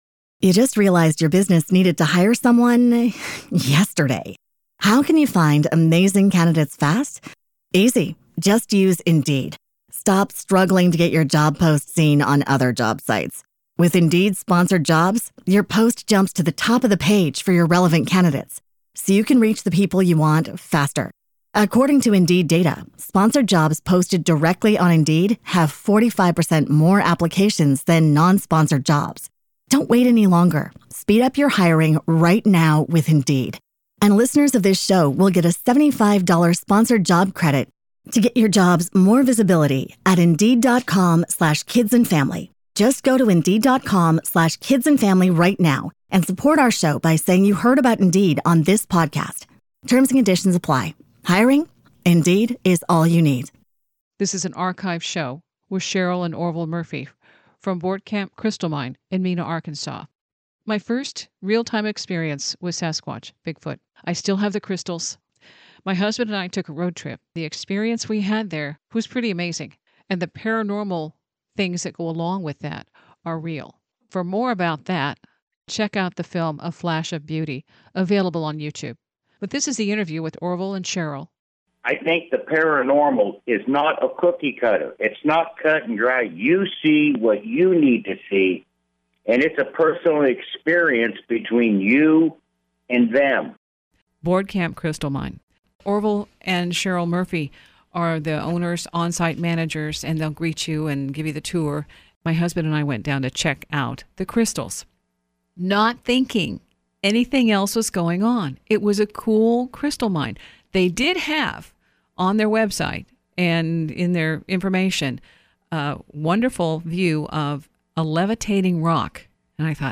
Revisiting an archived interview and my first encounter with Sasquatch / Bigfoot.